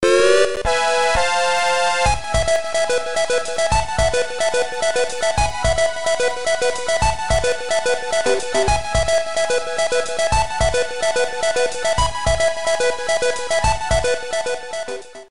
Edited Fade out